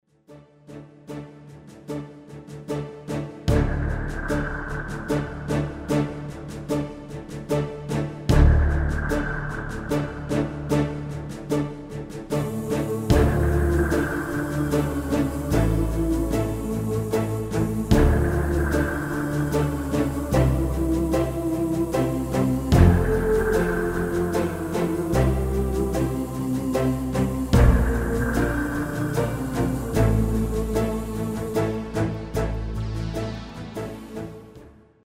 Demo/Koop midifile
Taal uitvoering: Instrumentaal
Genre: Pop & Rock Internationaal
Demo's zijn eigen opnames van onze digitale arrangementen.